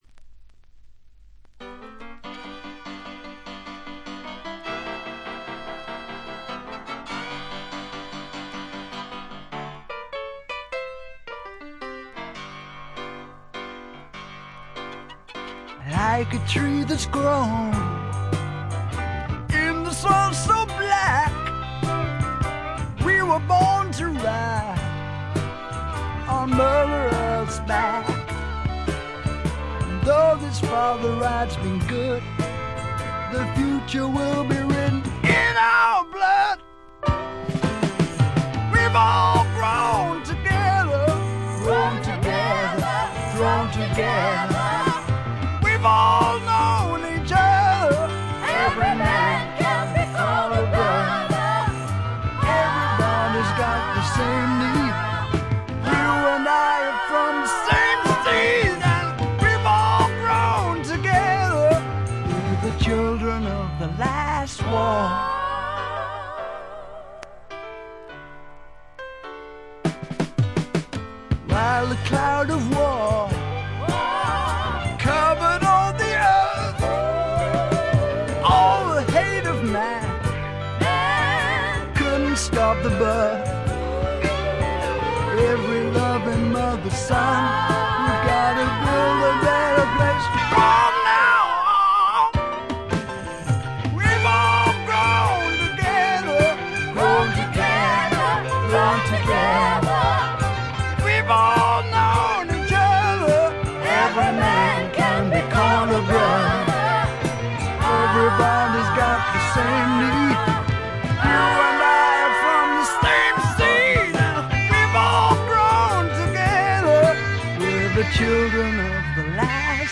わずかなノイズ感のみ。
典型的かつ最良の霧の英国、哀愁の英国スワンプ路線の音作りで、端的に言って「アンドウェラしまくり」です。
搾り出すような激渋のヴォーカルがスワンプ・サウンドにばっちりはまってたまりません。
試聴曲は現品からの取り込み音源です。